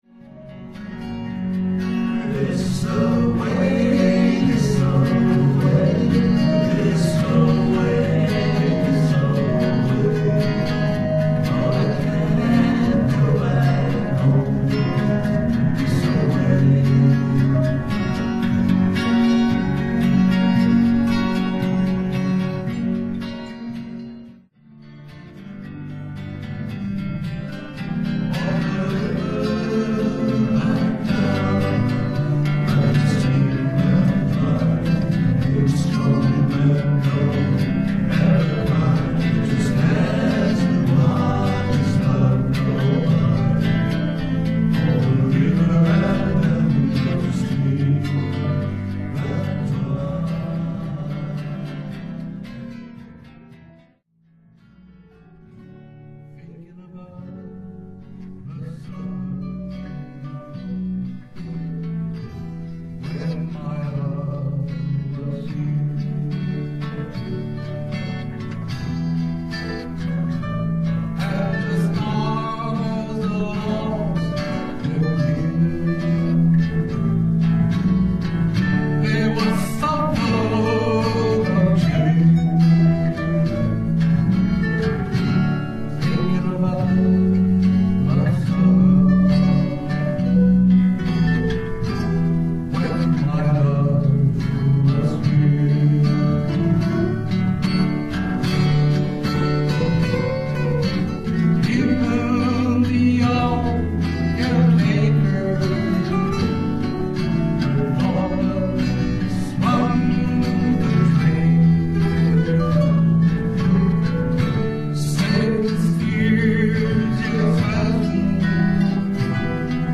当バンドは今年に入ってすでに４回の練習を重ね、１１日（日）が最終練習。先週２月４日（日）の練習の音源一部は下記から聴くことができます。